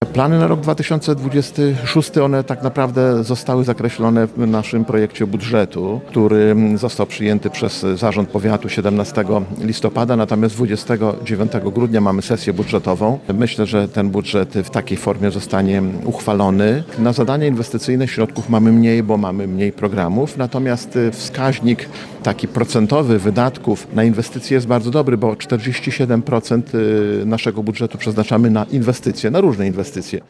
W Starostwie Powiatowym w Łomży odbyło się w poniedziałek (22.12) spotkanie wigilijne.
Starosta Łomżyński Lech Szabłowski ocenił, że miniony rok był bardzo udany, a nadchodzący również się taki zapowiada: